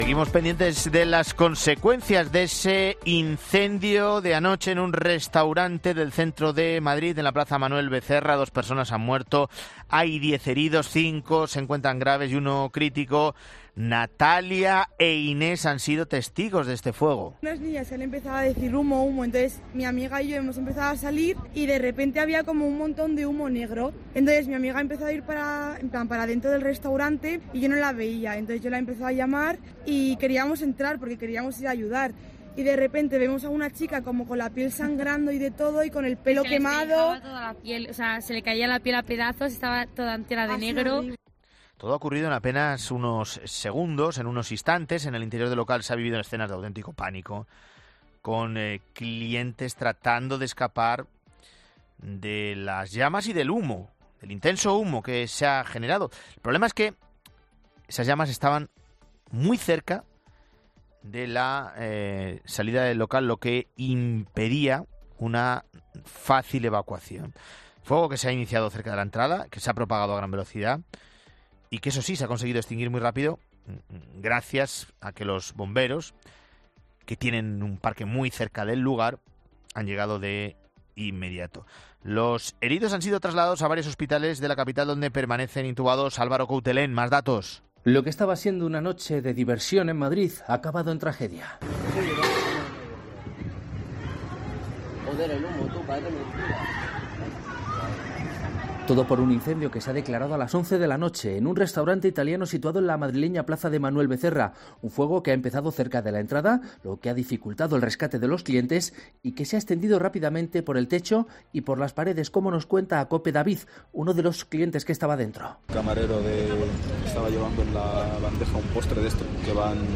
Angustiosos testimonios de clientes y testigos del incendio de un restaurante en Madrid en COPE